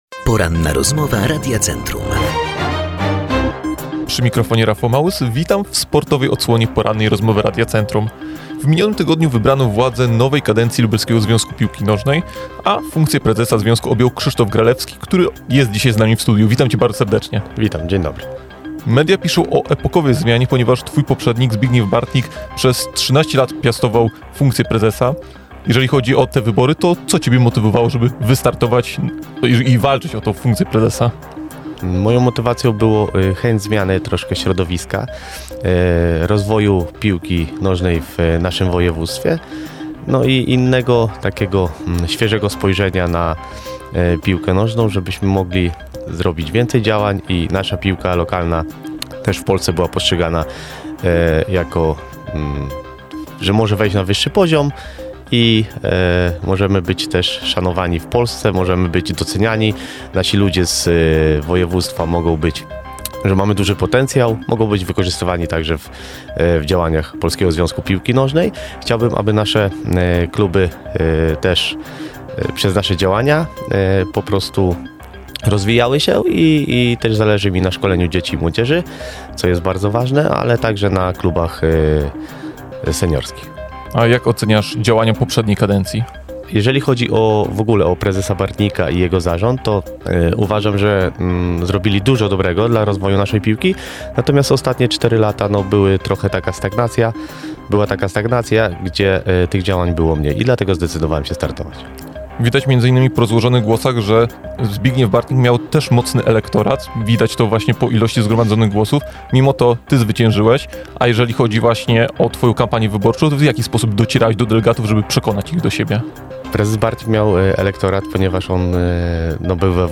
ROZMOWA.mp3